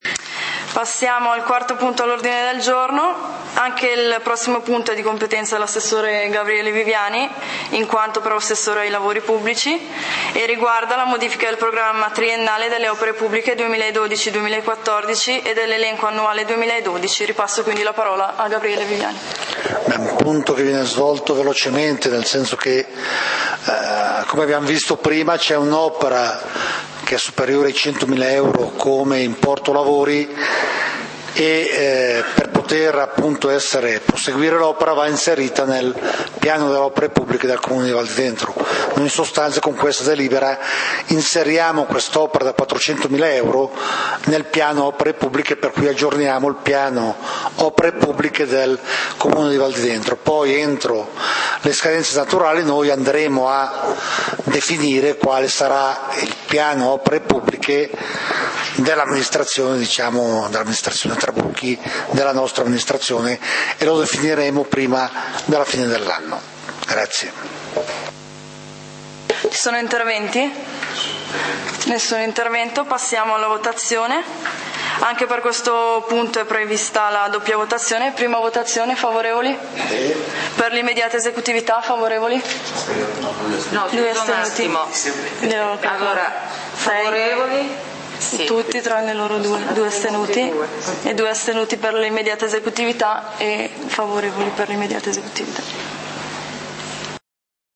Punti del consiglio comunale di Valdidentro del 24 Agosto 2012